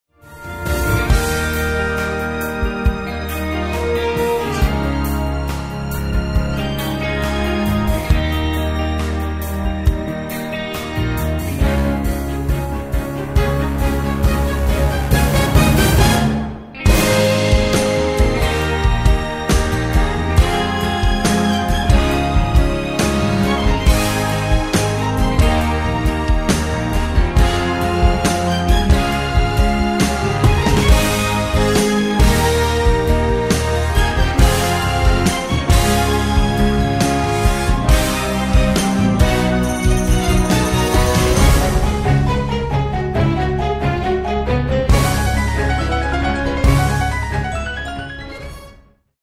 orchestral , backing track
Instrumental